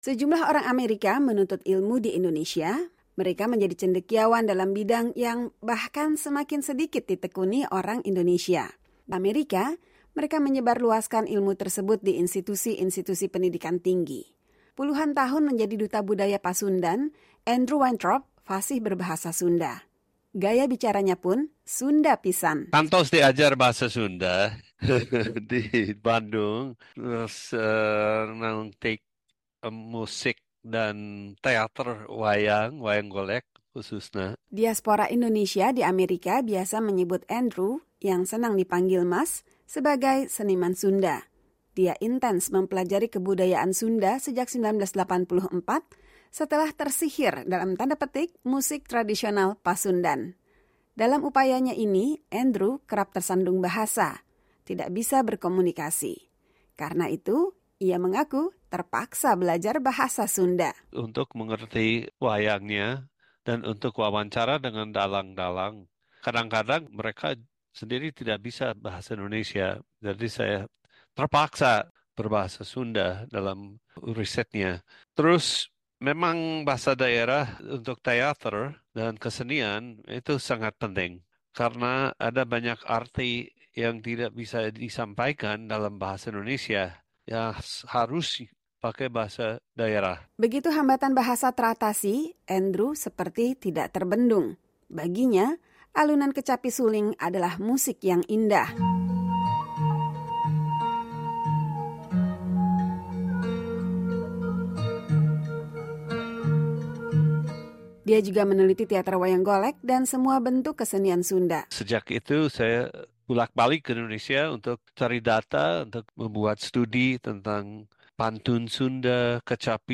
Gaya bicaranya pun Sunda pisan (kental sekali logat Sunda-nya).